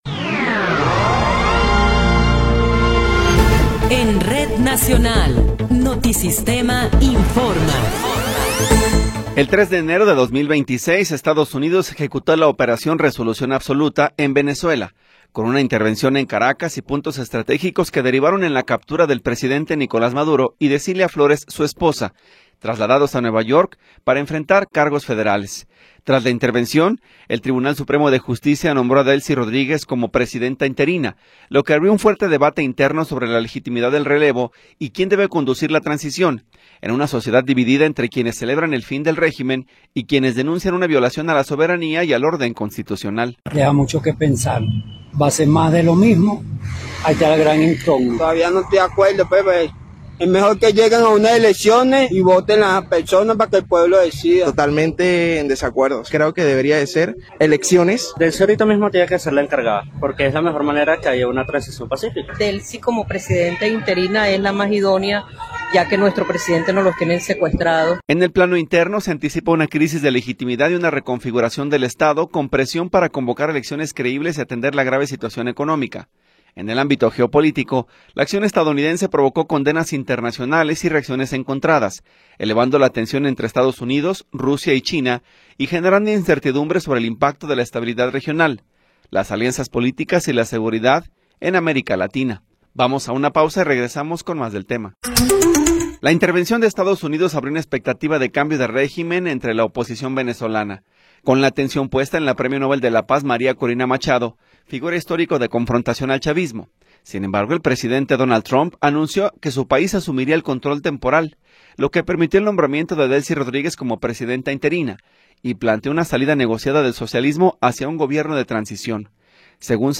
Noticiero 13 hrs. – 11 de Enero de 2026
Resumen informativo Notisistema, la mejor y más completa información cada hora en la hora.